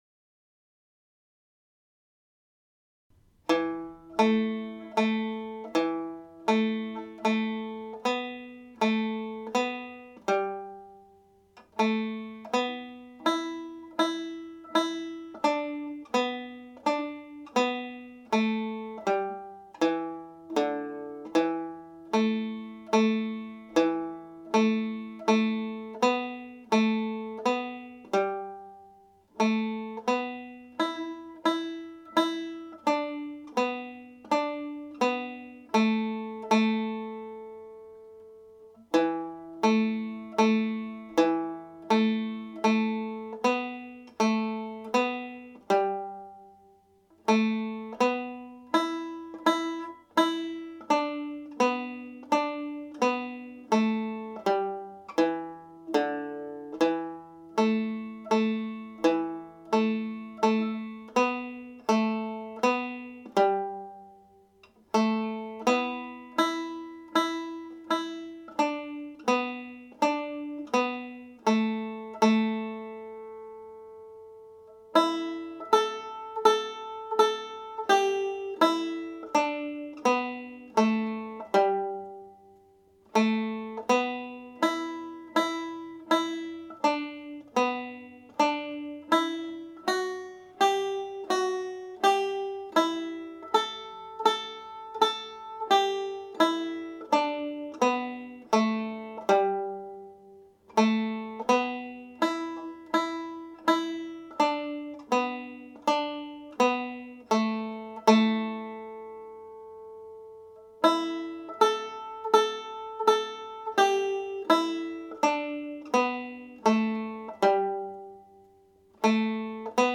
• banjo scale
Lilting Banshee played slowly
Lilting-Banshee-slow.mp3